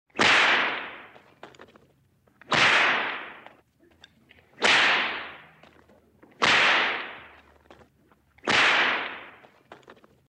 Вы можете слушать и скачивать резкие щелчки, свистящие удары в воздухе, отчетливые хлопки по поверхности.
Звуки удара хлыста